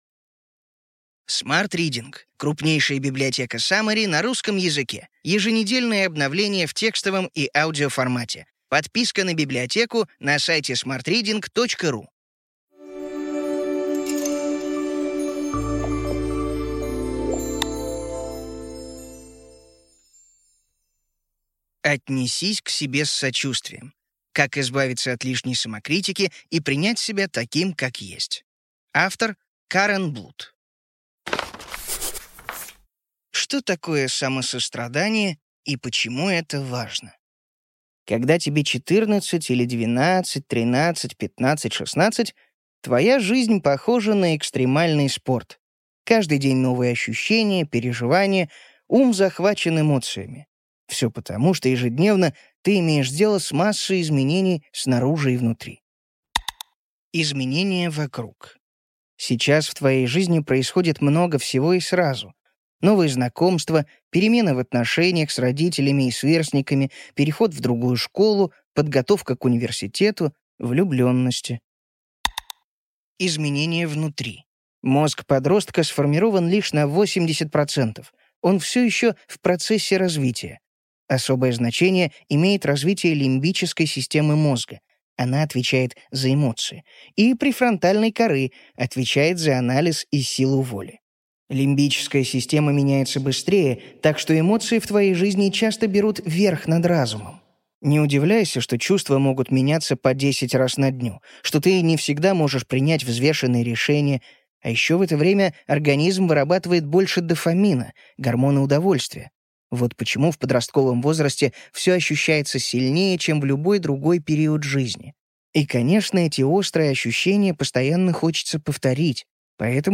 Аудиокнига Ключевые идеи книги: Отнесись к себе с сочувствием. Как избавиться от лишней самокритики и принять себя таким как есть.